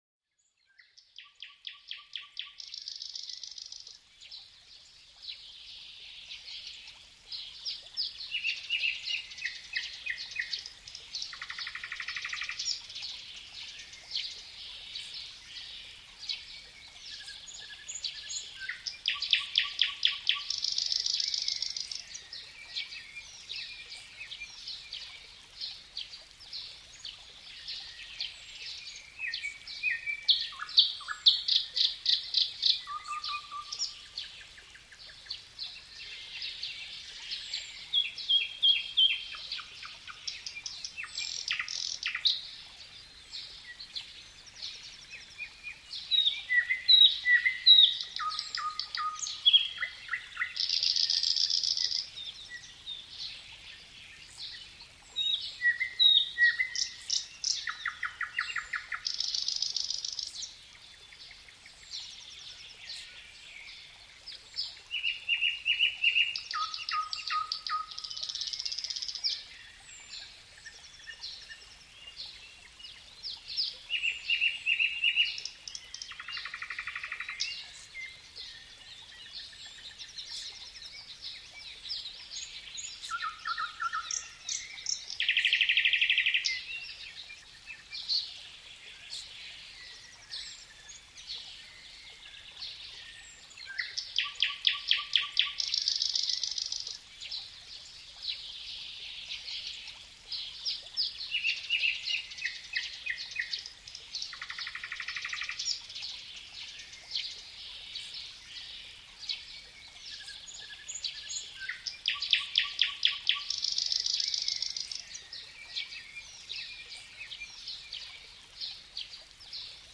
Relájate con el suave canto de los pájaros en la naturaleza – perfecto para dormir fácilmente